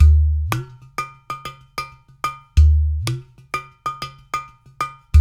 93 -UDU 06L.wav